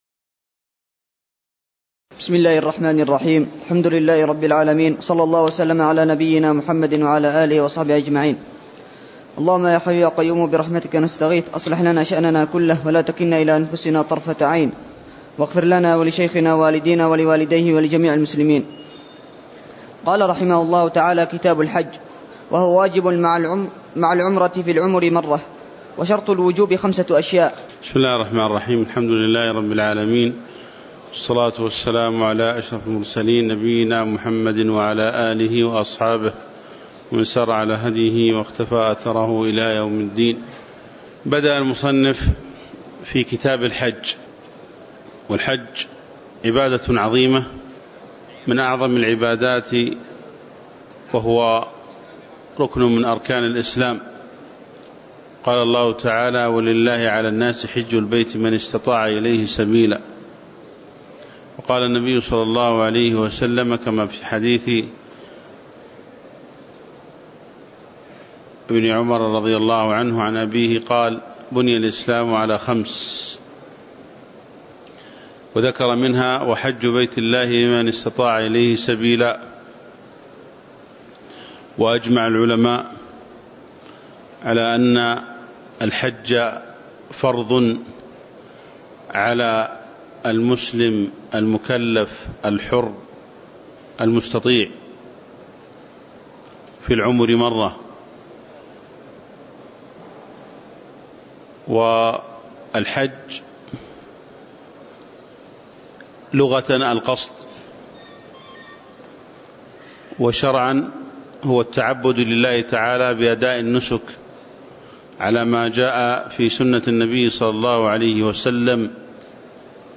تاريخ النشر ٧ ذو القعدة ١٤٤٠ هـ المكان: المسجد النبوي الشيخ